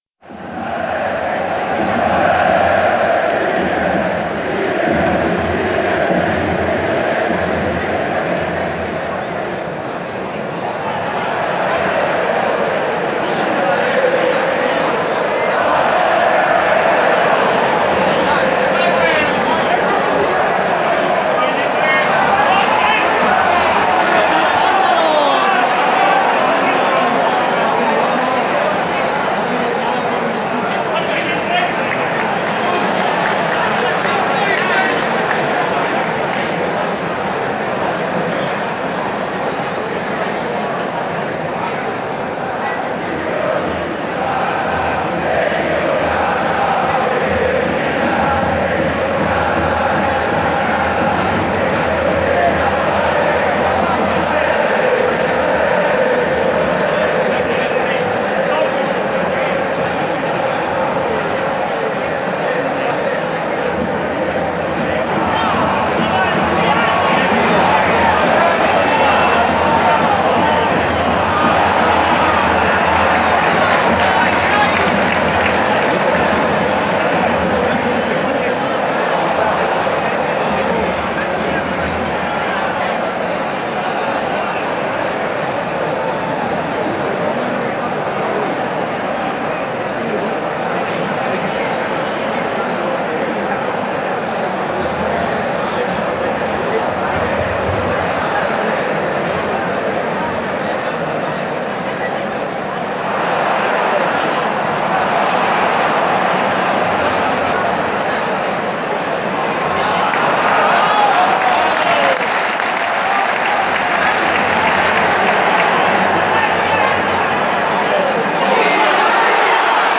Crowd chants and noise from Inter Milan 0-0 Lazio in December 2017 at the San Siro stadium, Milan.